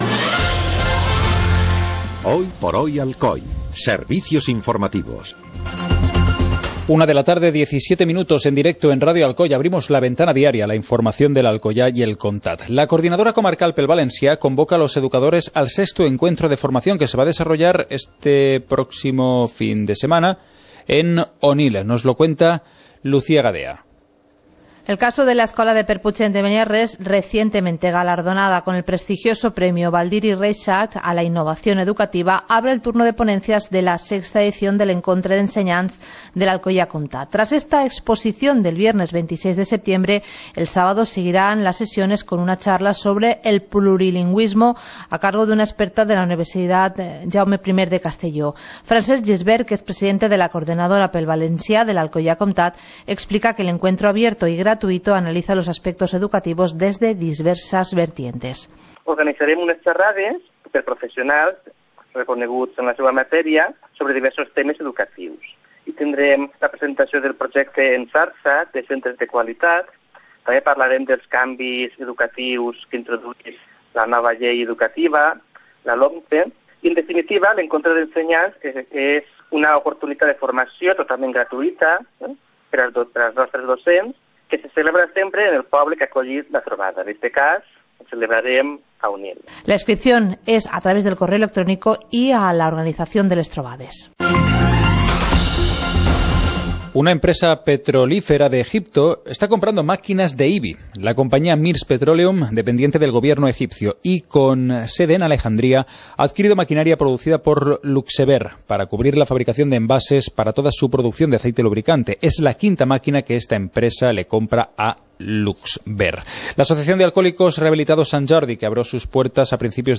Informativo comarcal - jueves, 25 de septiembre de 2014